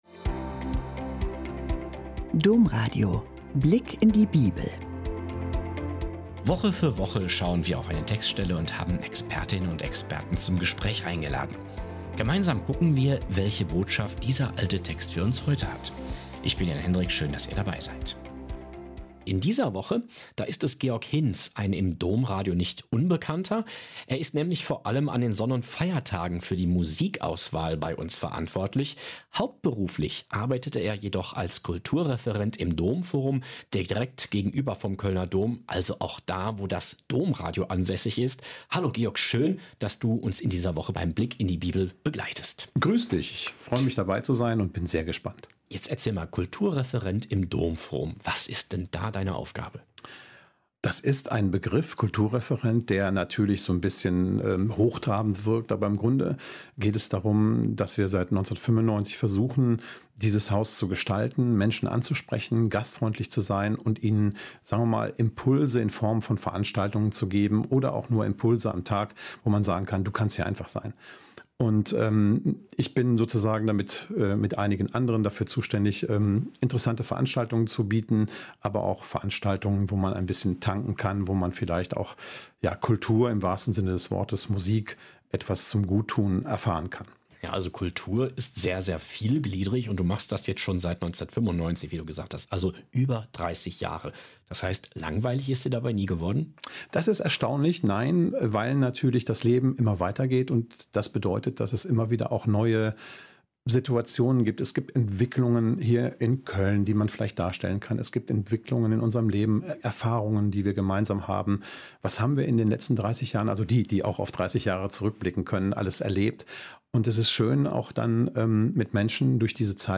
Heilung beginnt dort, wo Menschen sich berühren lassen – innerlich wie äußerlich. Ein Gespräch, das Bibel und Alltag überraschend nah zusammenbringt und Lust macht, tiefer zuzuhören.